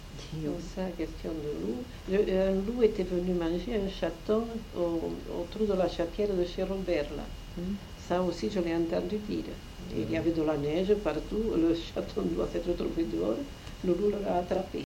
Lieu : Aulus-les-Bains
Genre : conte-légende-récit
Type de voix : voix de femme
Production du son : parlé
Classification : récit de peur